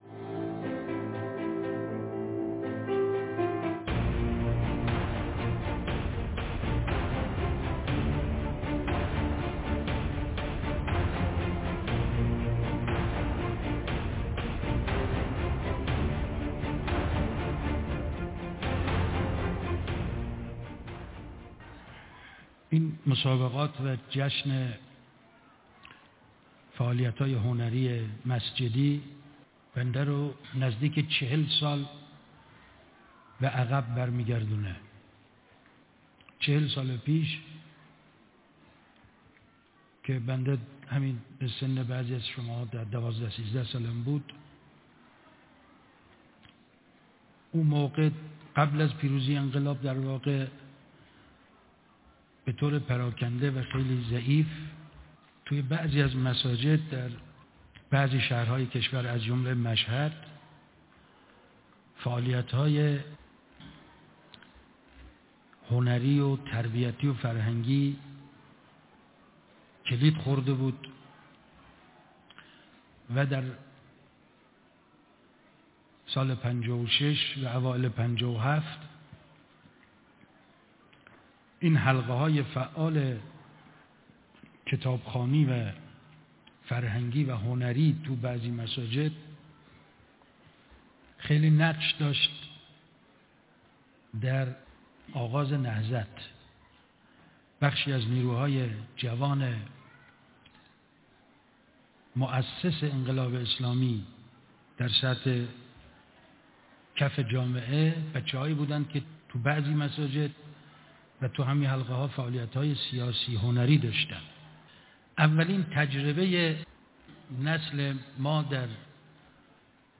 روز جهانی مسجد/ درجمع فعالان هنری مساجد / ۱۳۹۶